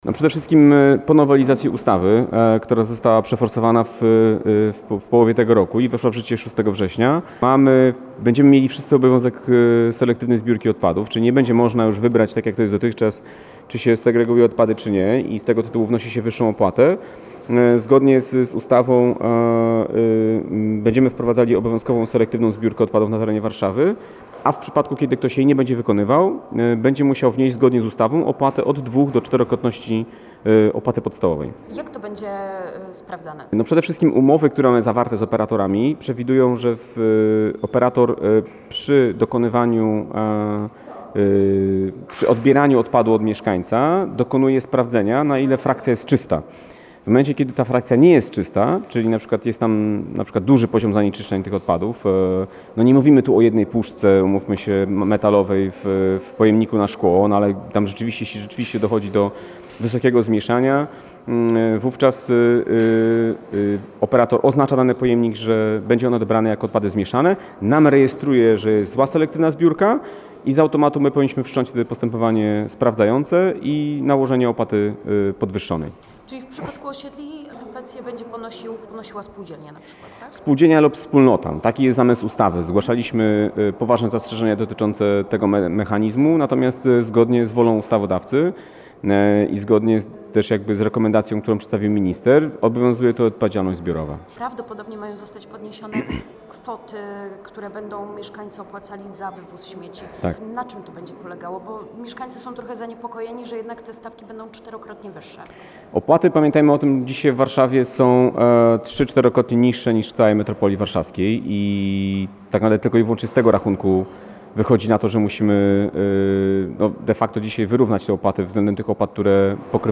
Wypowiedź wiceprezydenta Michała Olszewskiego: